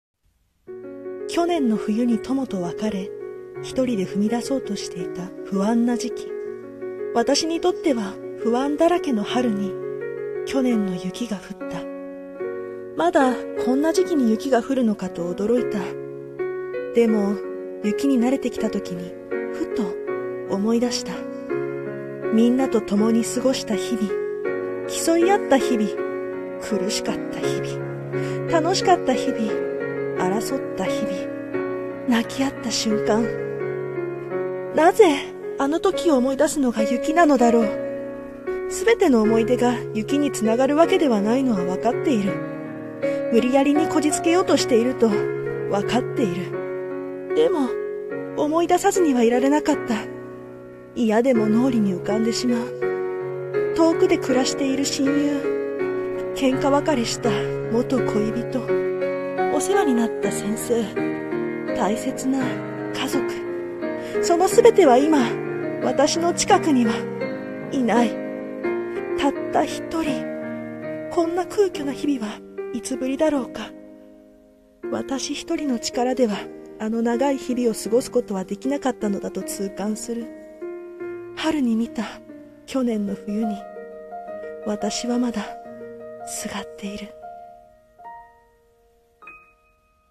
春の雪 【朗読者】